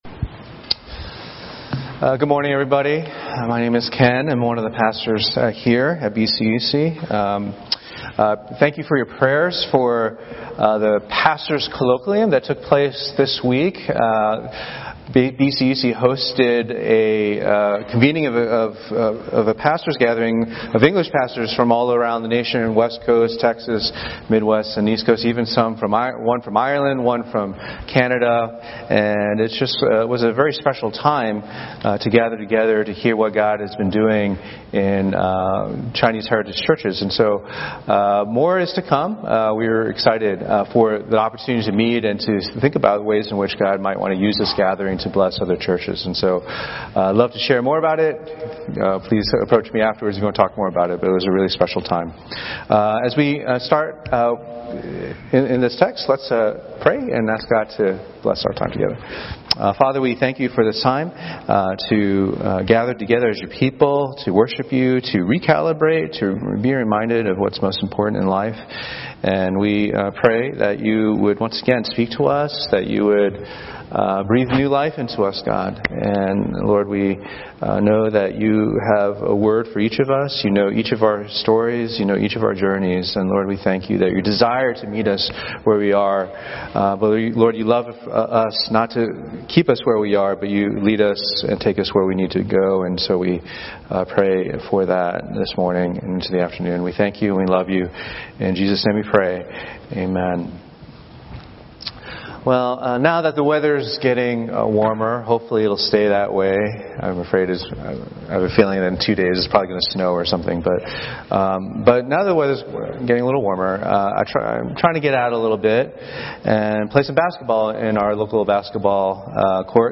Sermons - Page 13 of 74 | Boston Chinese Evangelical Church